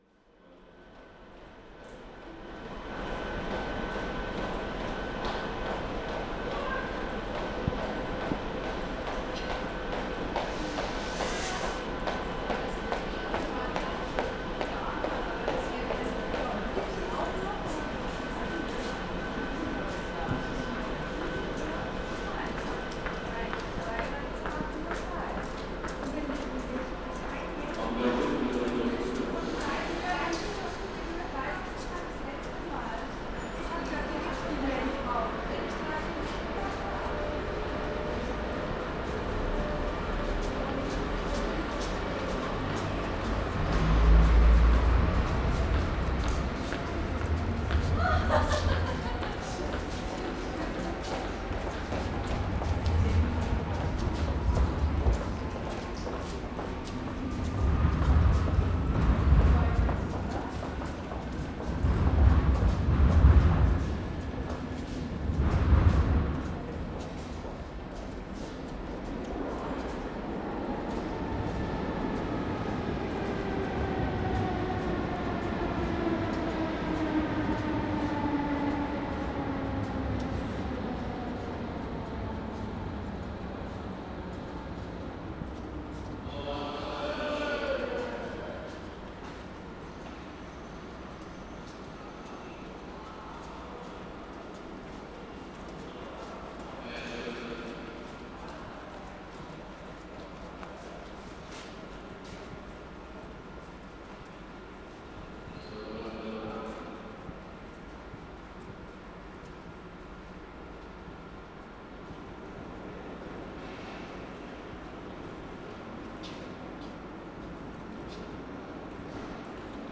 Hauptbahnhof Railway Station, passage (gong and announcement at 6:47) 7:47